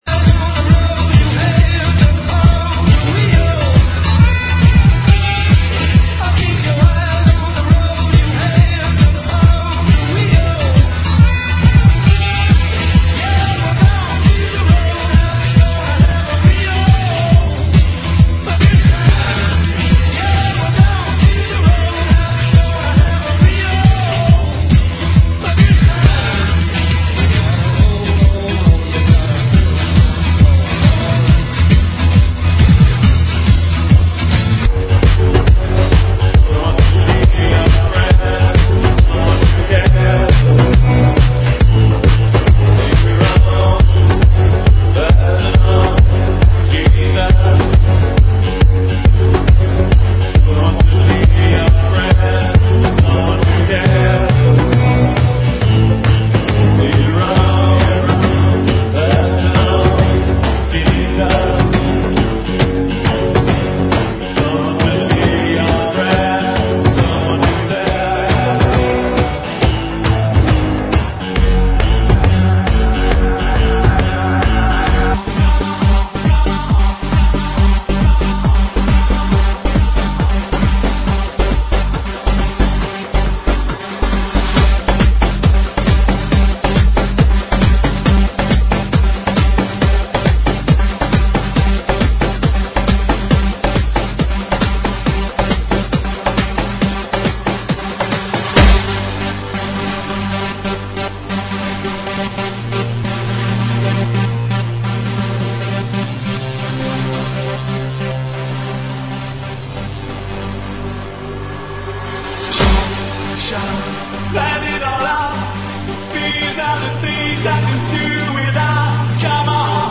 GENERO: ROCK – POP – 70S 80S